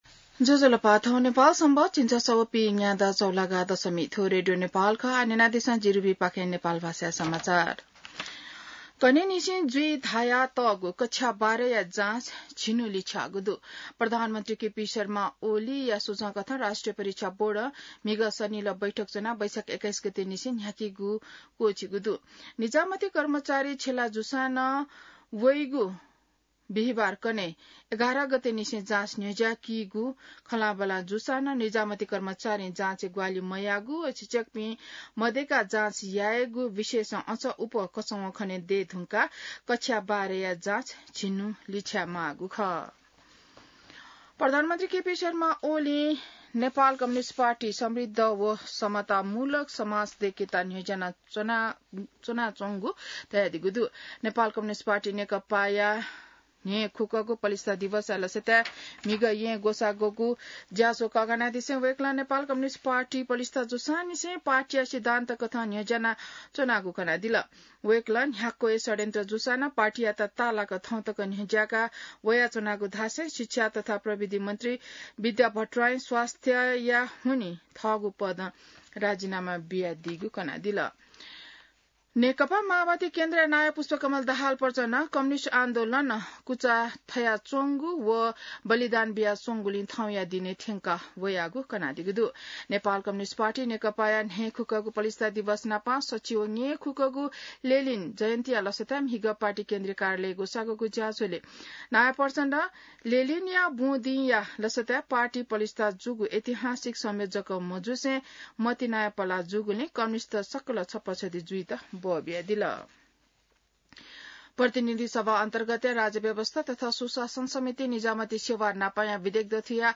नेपाल भाषामा समाचार : १० वैशाख , २०८२